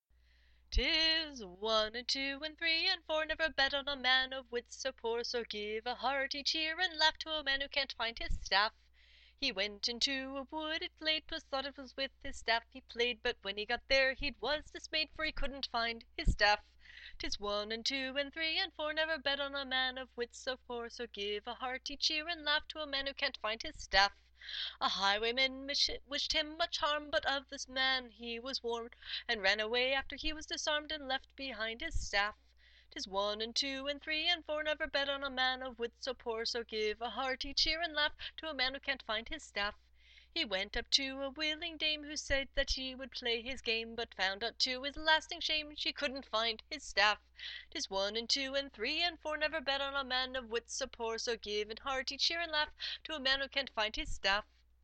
Sooooo…I’ve never written a song and performed it in less than five minutes after someone said something hilarious at a LARP.